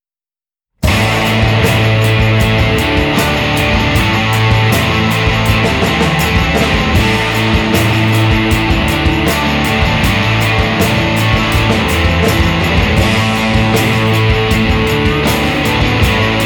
shoegaze, haphazard psychedelia, psych pop.
Big guitars and a drunken swagger!